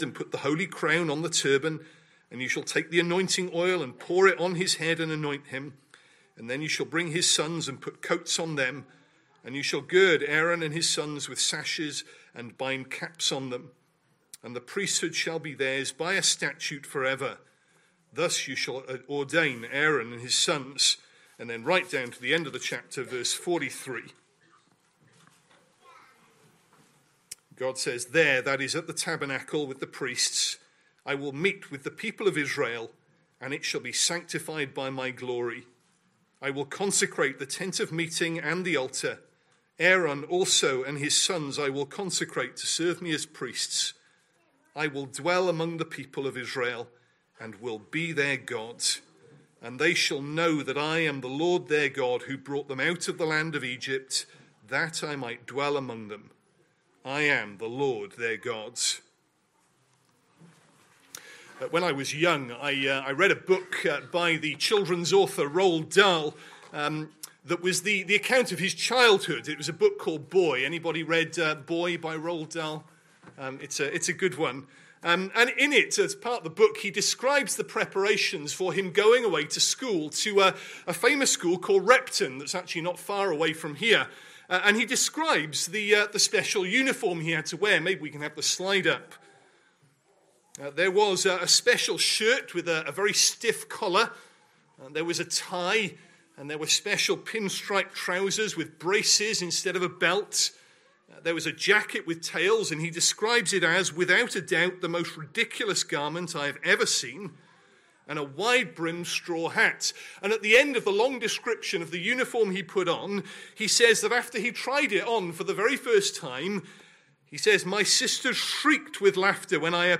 Christ Church Sermon Archive
Sunday AM Service Sunday 12th October 2025 Speaker